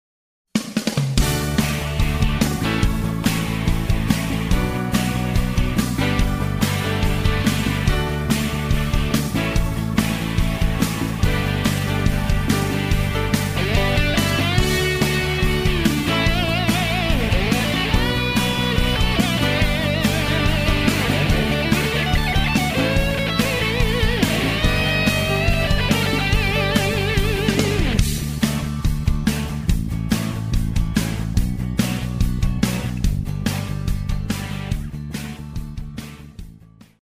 Muestras de las pistas
coros